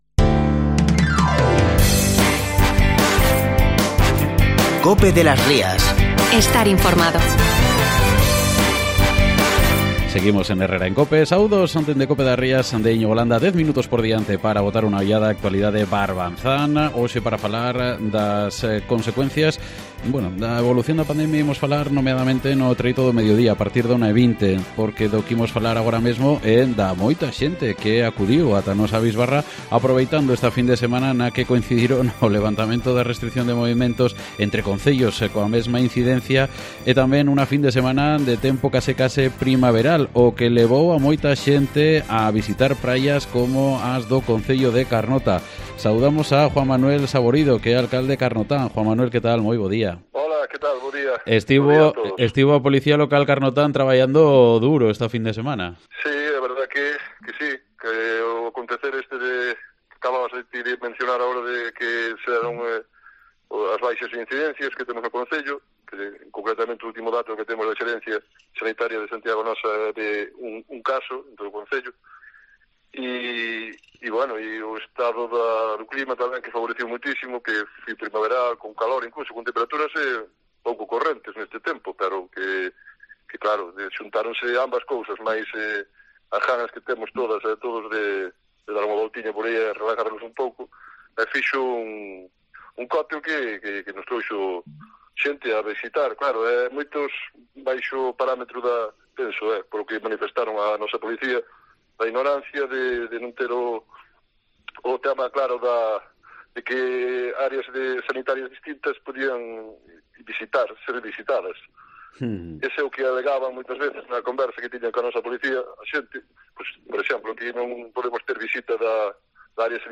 Entrevista con el alcalde de Carnota, Juan Manuel Saborido, sobre las sanciones impuestas este fin de semana